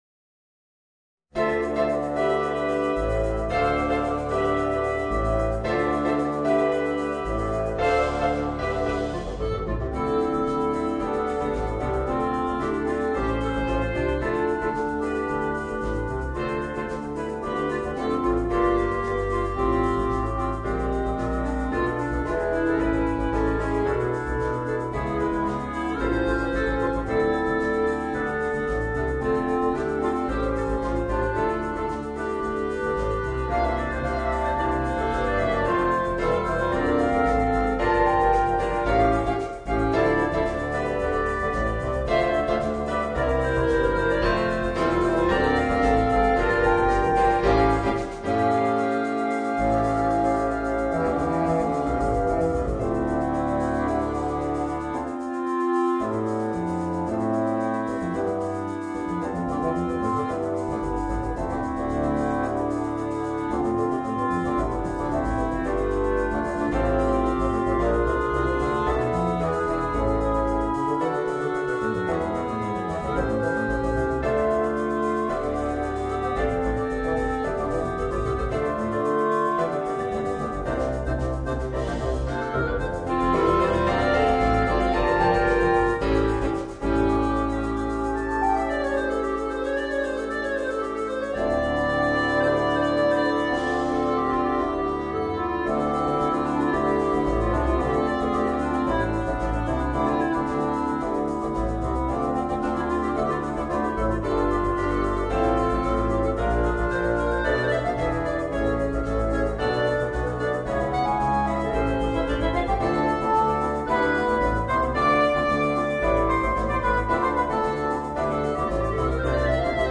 Voicing: Woodwind Quartet and Rhythm Section